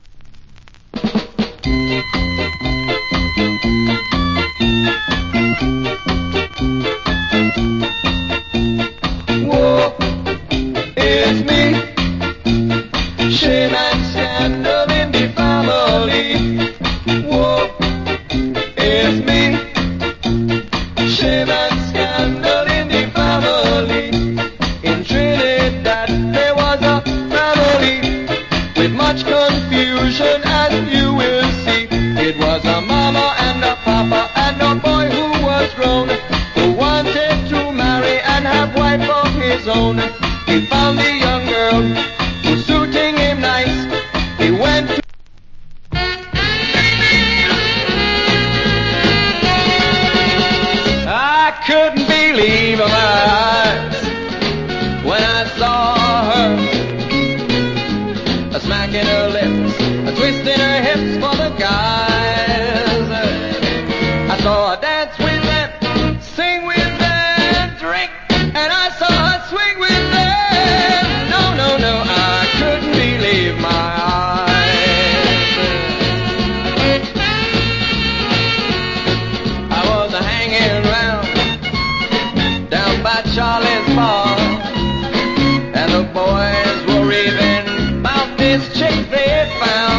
Ska Vocal.